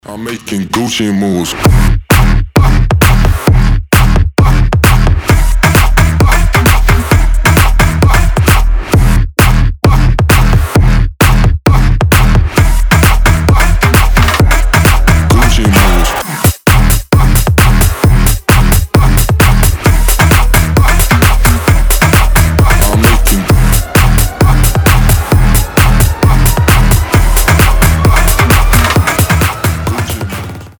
• Качество: 320, Stereo
громкие
жесткие
Electronic
EDM
мощные басы
Стиль: electro house